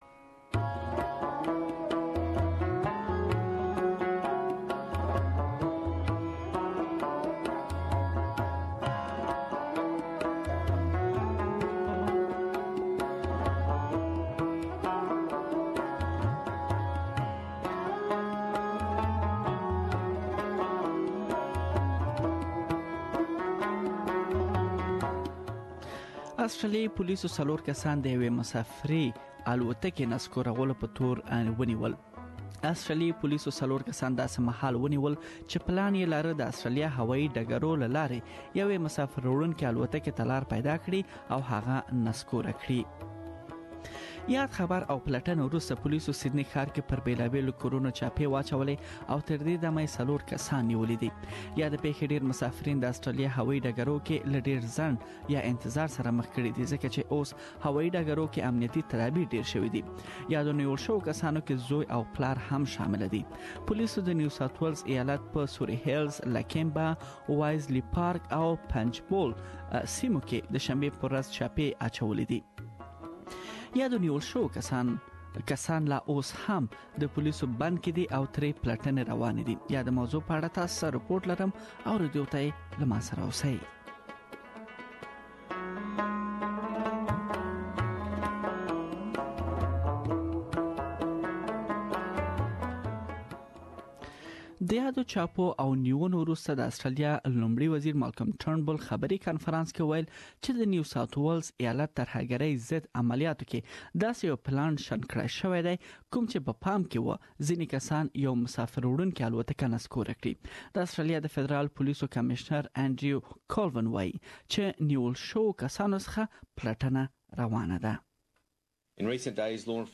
Home Affairs Minister Peter Dutton has flagged the possibility of more permanent changes. Please listen to the full report here.